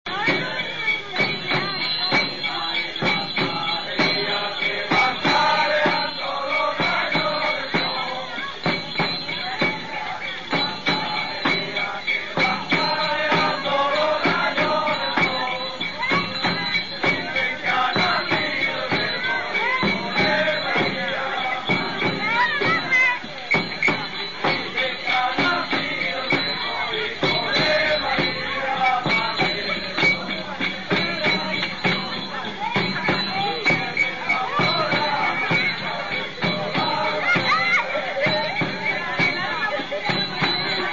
El "tan tán tan tararán tan" de la tambora, acompasado, sonoro y enérgico les llega al alma a los cambileños.
Hay muchas estrofas, el poeta no escatimó, pero la gente repite sólo unas pocas y éstas cantadas con desorden y un poco atropelladamente.
También algunos echan sus traguillos, los que van toda la noche acompañando con sus instrumentos de cuerda.